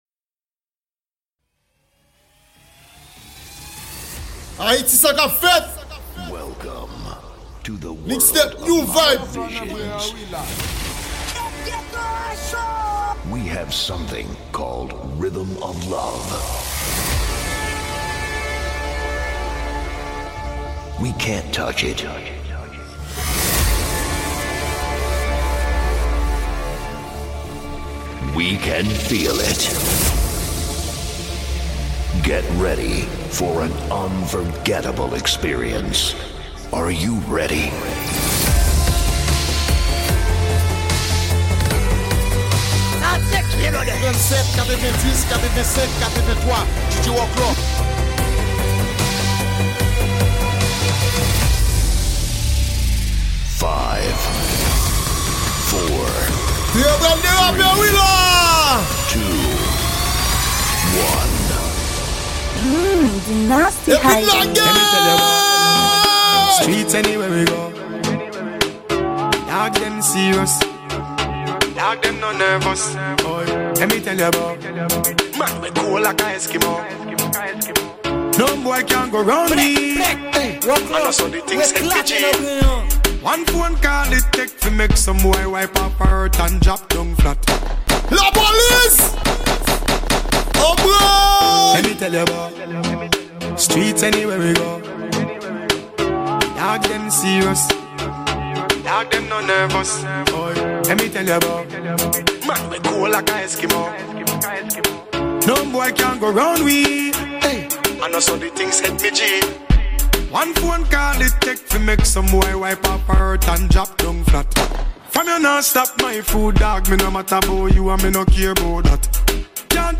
Genre: Dj Mixes.